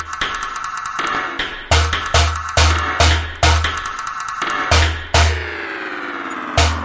对比Bwow合成器
标签： 140 bpm Electronic Loops Synth Loops 2.31 MB wav Key : Unknown
声道立体声